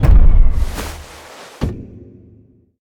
Sfx_tool_hoverpad_park_01.ogg